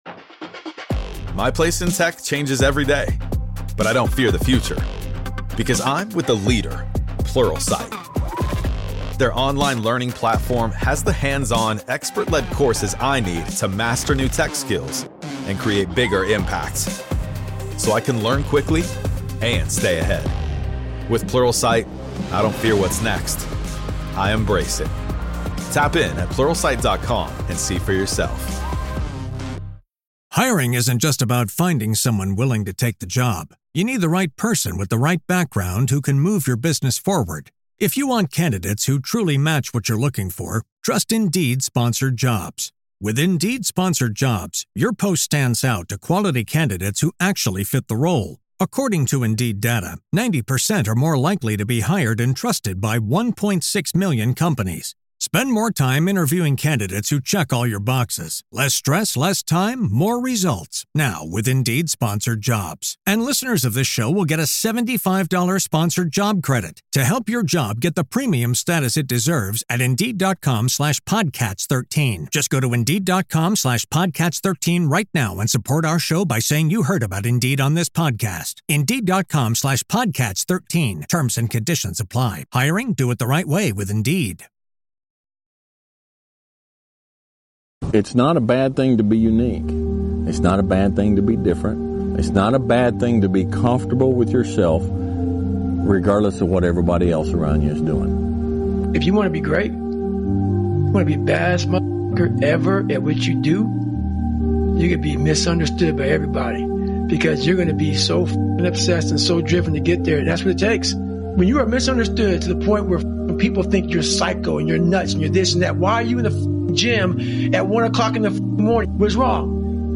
This motivational speech featuring Kanye West is here to inspire you to follow your own path and be proud to be different.